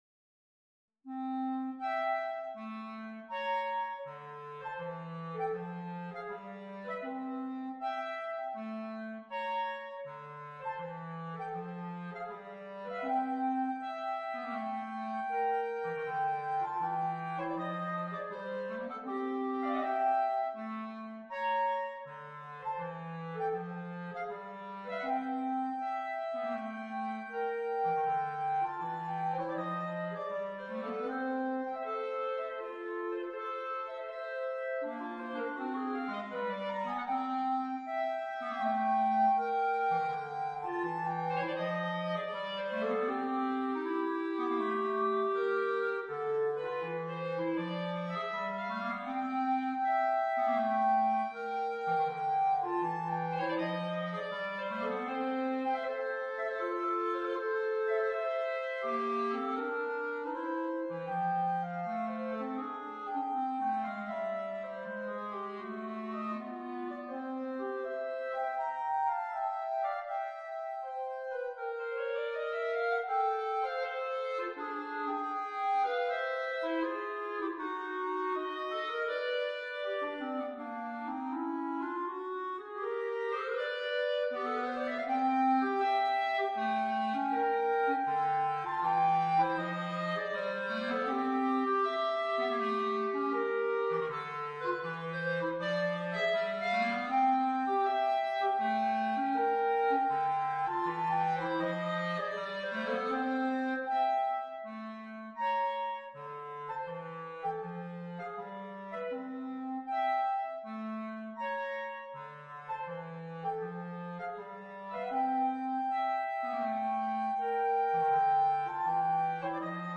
per due clarinetti